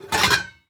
metal_lid_movement_impact_12.wav